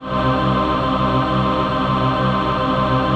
VOICEPAD19-LR.wav